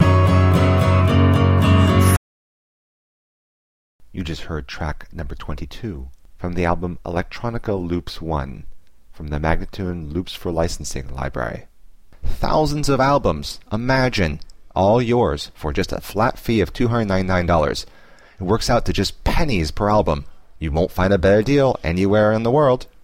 106-FNo-beatundercontrol-1041